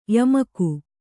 ♪ yamaku